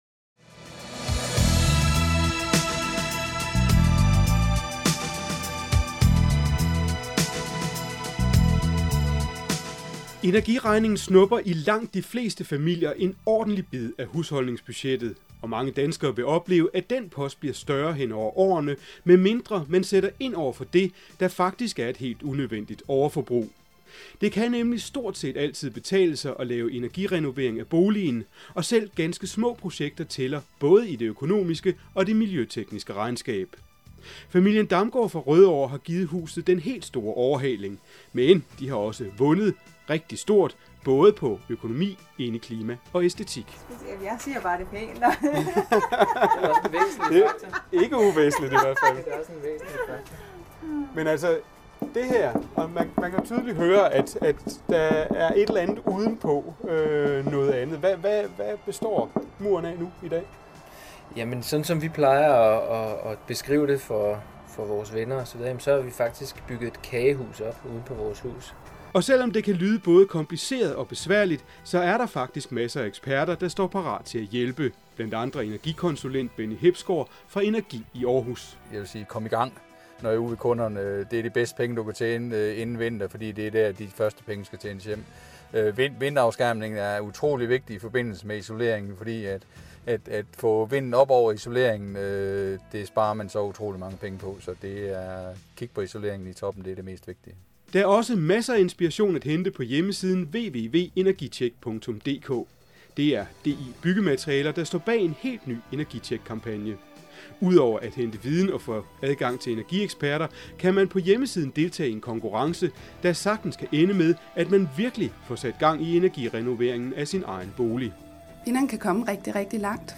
5 Radioprogrammer om energi og boligen I forbindelse med kampagnen "Energitjeck" har kombic for DI Byggematerialer produceret fem radioprogrammer.